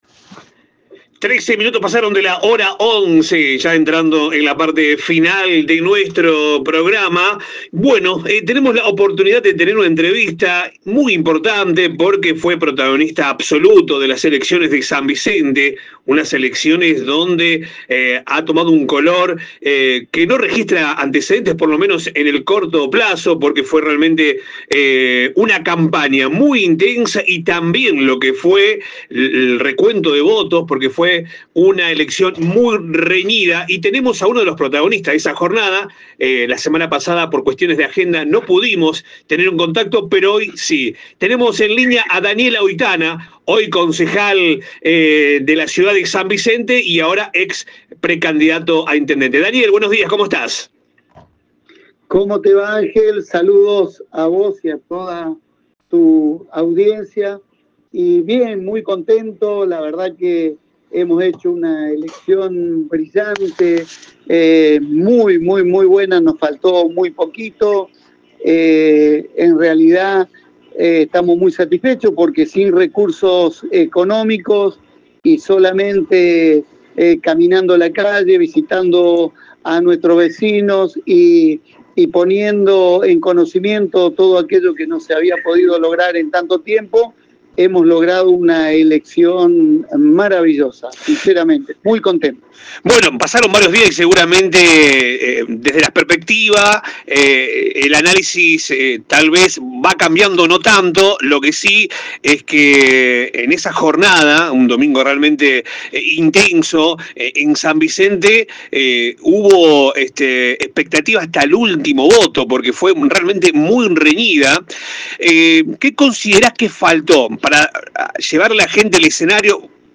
El concejal de la ciudad de San Vicente realizo un analisis de los resultados de las elecciones en su ciudad, afirmo que solo le faltaron 42 votos para ganarle la interna al actual intendente Gonzalo Aira y dejo varios concepto de cara a todo lo que viene Nota Completa a Daniel Oitana